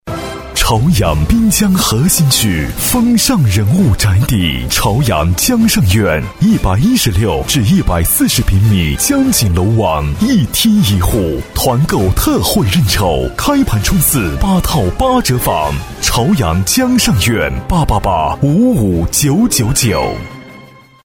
• 男S317 国语 男声 广告-朝阳江上院-房地产广告-会红红大气 沉稳|积极向上|素人